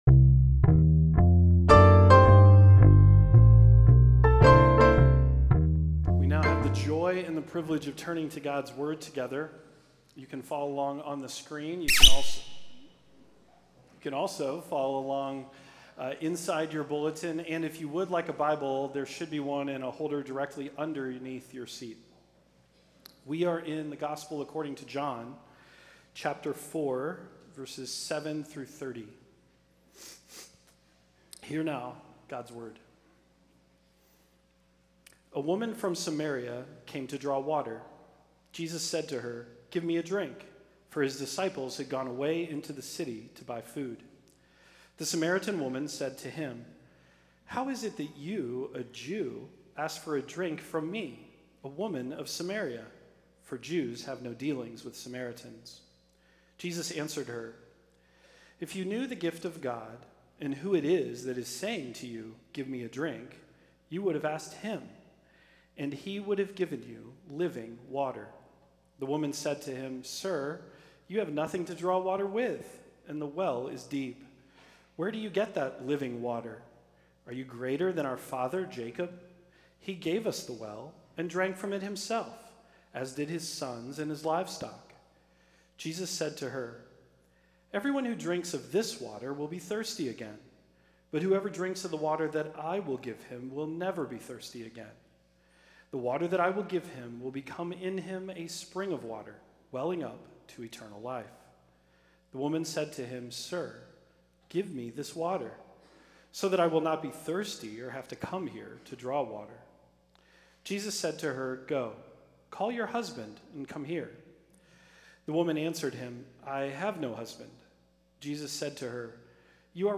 Sermon
Service Type: Sunday Worship